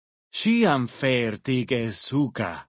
Category:Dead Horses pidgin audio samples Du kannst diese Datei nicht überschreiben.